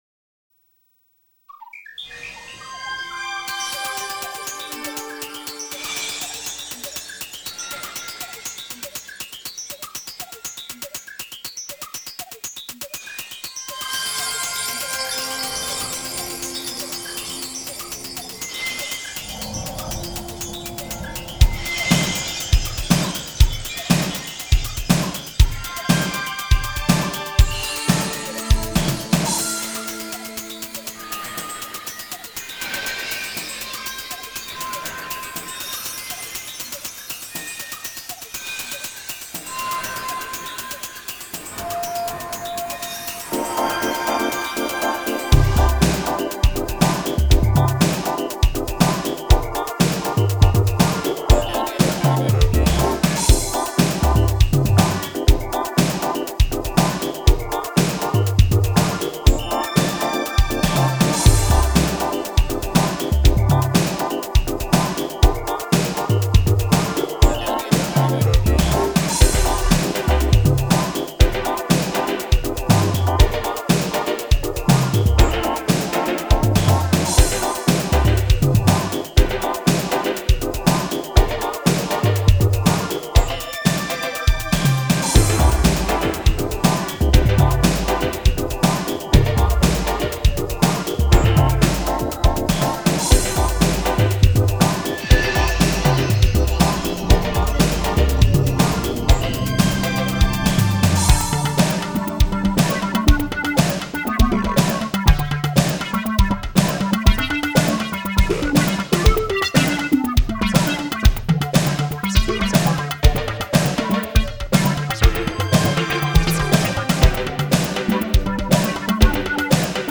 Atari ST computer, EMAX II sampler, Roland R5 drum machine, VCR, and SMPTE processor.
For this exhibition, new music was produced on this equipment for a segment of the 1992 CGI video Beyond The Mind's Eye.